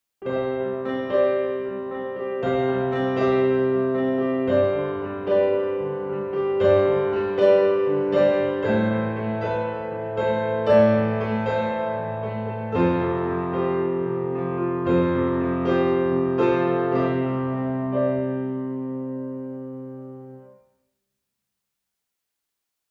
Was ist eigentlich, wenn ein Dreiklang weder Moll noch Dur, noch übermäßig, noch vermindert ist? Das Ganze könnte dann so klingen:
Klangbeispiel Sus-Akkorde
Du hörst im Tonbeispiel sogenannte Sus-Akkorde.
Außerdem kann man den Akkord in der Popmusik auch als Wechselakkord einsetzen, das heißt, man wechselt die ganze Zeit zwischen dem “normalen” Dreiklang und den Sus2- und Sus4-Akkorden, so wie das im Hörbeispiel am Anfang zu hören ist.
Sus_Akkorde_Wechselakkord.mp3